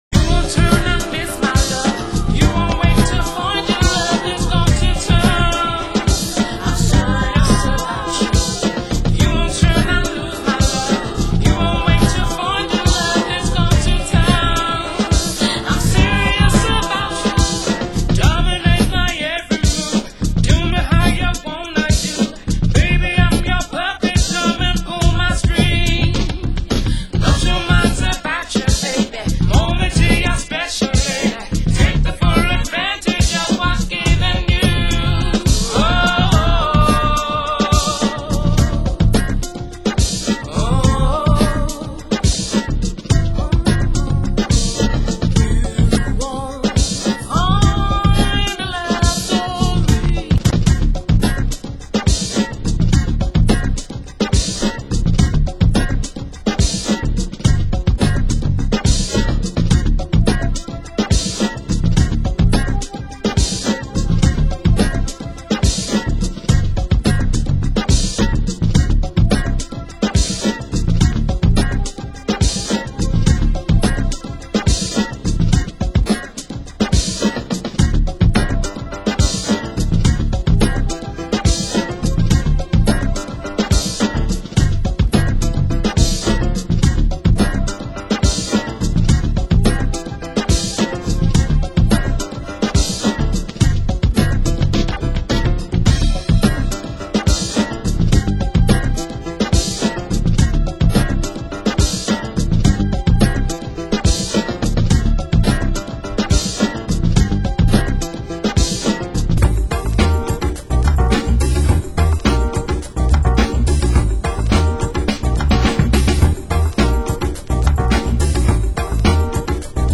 Genre: Soul & Funk
vocal